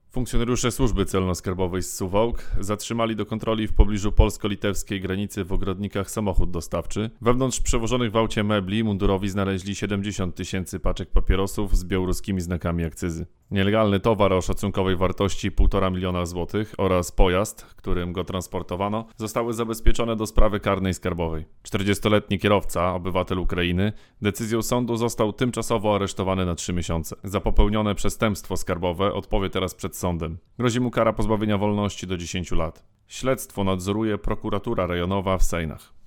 Podlaska KAS przejęła papierosy ukryte w transporcie mebli (wypowiedź mł. rew.